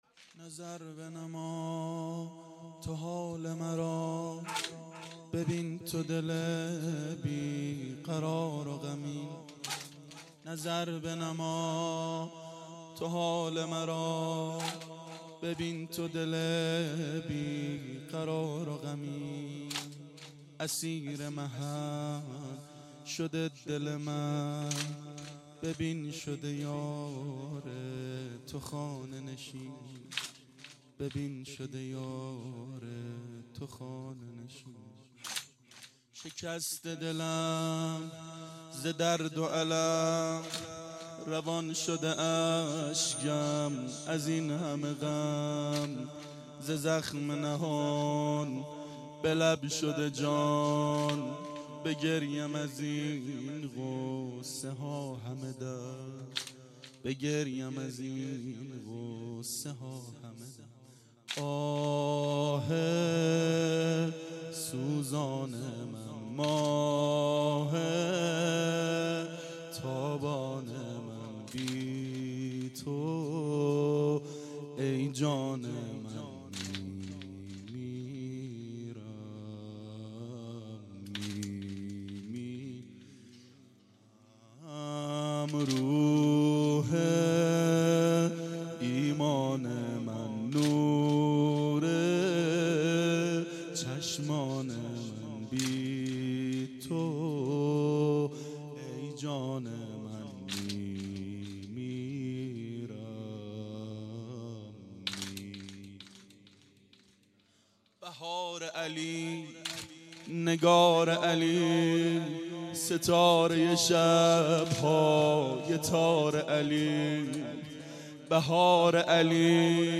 شب شهادت حضرت زهرا سلام الله علیها 93
• فاطمیه